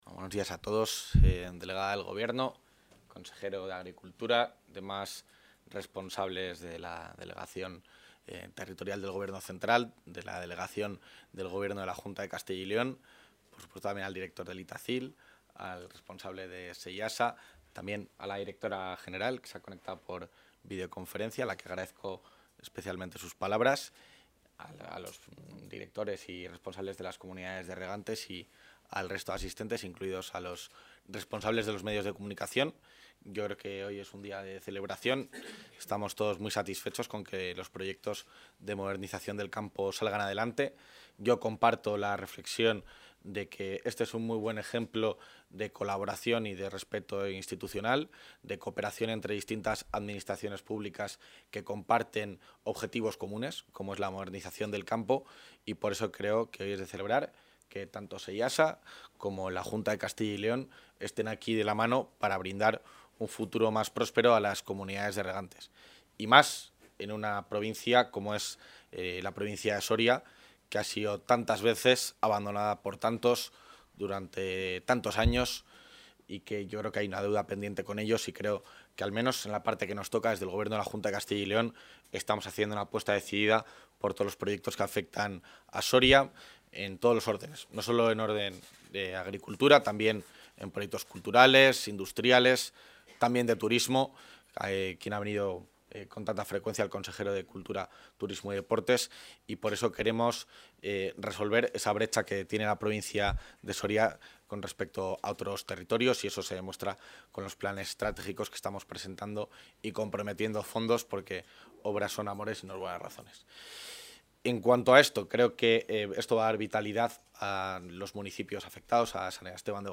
El vicepresidente de la Junta de Castilla y León, Juan García-Gallardo, ha participado hoy en Soria en el acto de firma del convenio...
Intervención del vicepresidente.